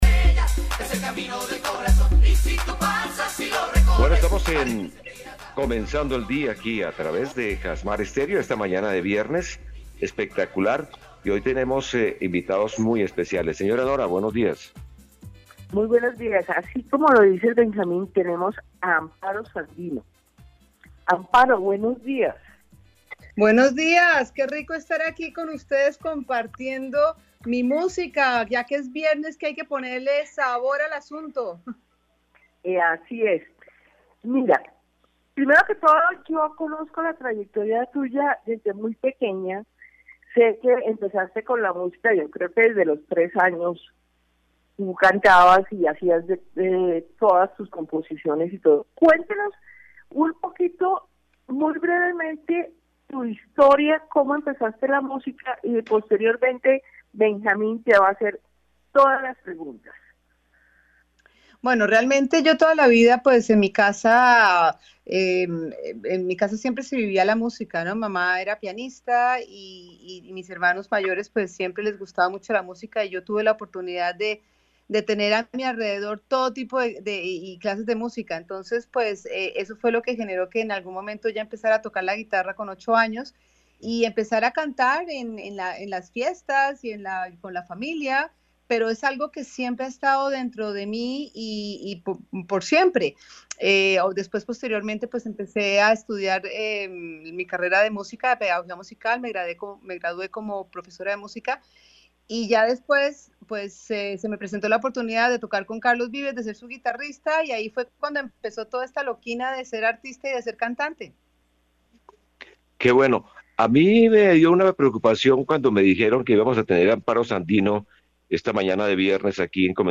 gran cantante Colombiana estuvo en entrevista con nosotros para lanzar su nuevo éxito llamado no me lastimes